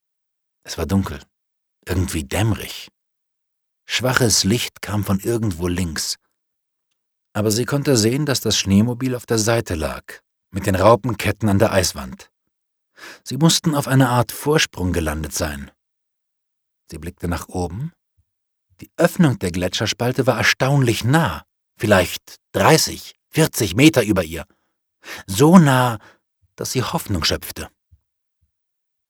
deutscher Sprecher und Schauspieler.
Kein Dialekt
Sprechprobe: Werbung (Muttersprache):
voice over talent german